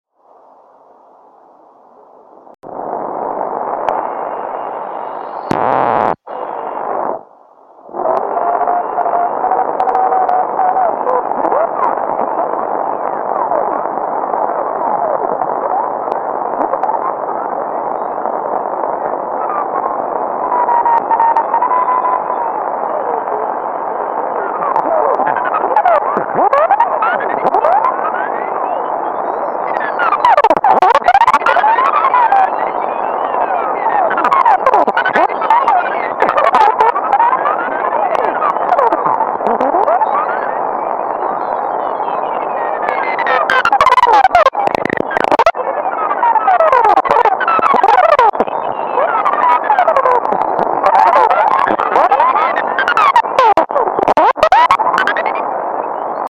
Попробовал записать приемник на сотовый тлф (вместо микрофона гарнитуры подключен к выходному трансформатору приемника), пока не очень хорошо получилось, но для представления, как звучит комнатная антенна 14 см диаметром и регенератор с потреблением 10 мкА
АА тест громыхает, я сделал очень быстрый проход по диапазону 14 МГц, в начале записи манипулирую уровнем регенерации, видно что шумы (и помехи) возрастают очень сильно, ну и уровень записи надо будет сделать поменьше в дальнейшем
RegenRXscanAA14MHz.mp3